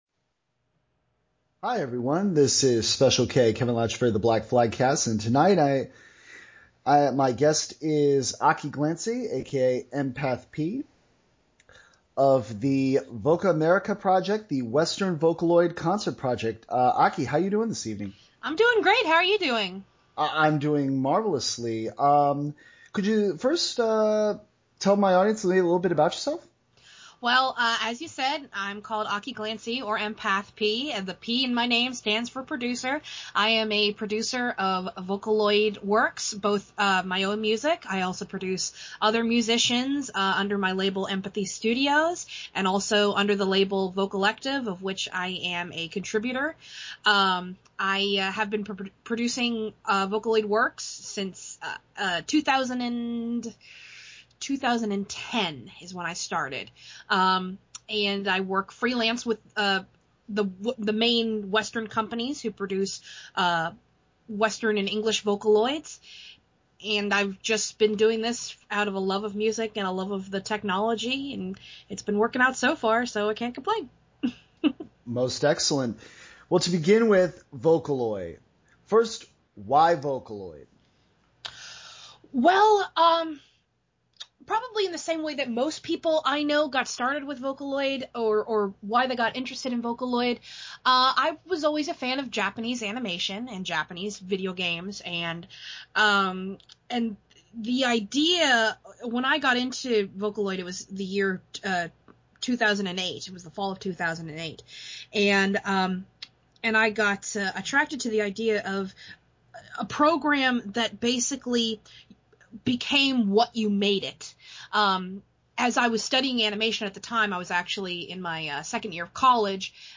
Venus-Powered Work: My Interview with Cherami Leigh
We discussed the voice acting business, fandom through the years, and, as a special treat for fans of Sebastian Michaelis and Francis Bonnefoy (France/Hetalia), a discussion on J. Michael Tatum. It was an honor and privilege to do this interview.